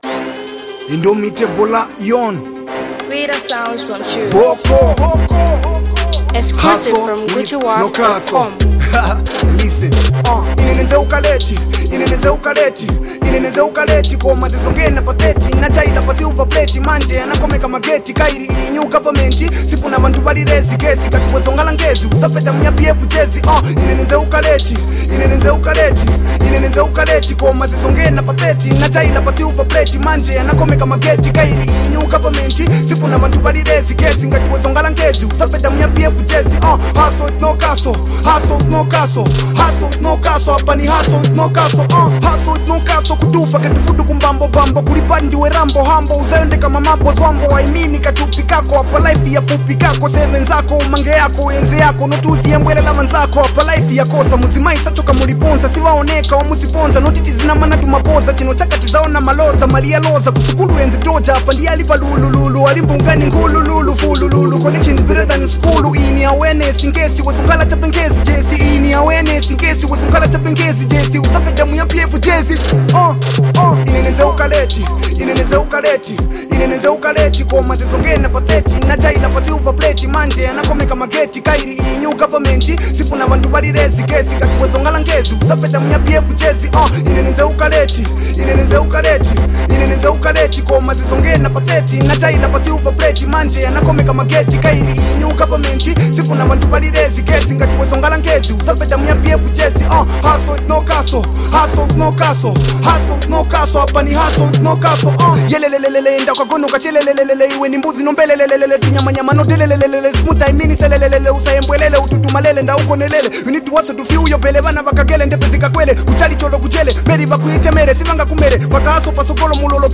a songwriter and rapper.
is another  brand new Fabulous-wave jam